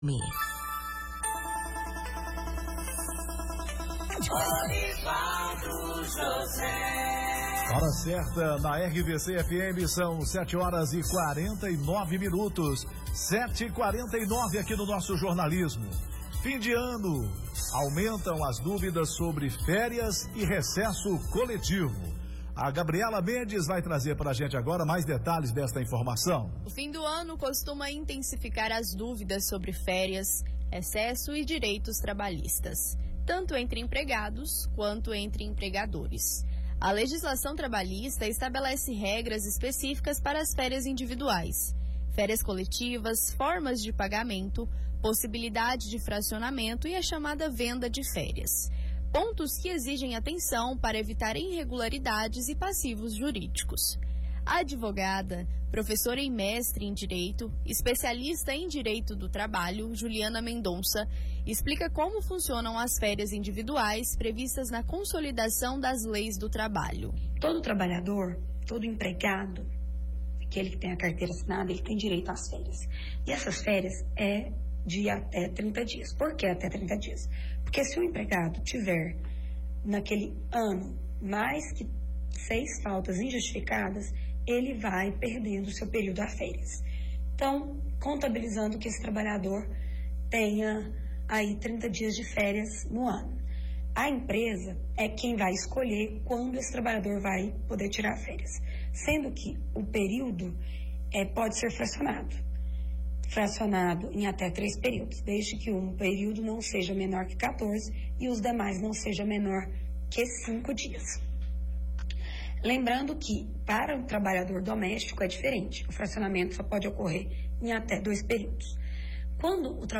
Radio-RVC-90.3-FM-Goianesia-ferias-coletivas-individuais-e-recesso-.mp3